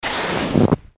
Heavy Boot Step In Deep Frozen Snow Sound Effect
Realistic recording of a single boot pressing into deep, hard, frozen snow. Captures the crunchy, rustling sound of snow compacting underfoot.
Heavy-boot-step-in-deep-frozen-snow-sound-effect.mp3